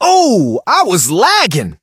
brock_death_01.ogg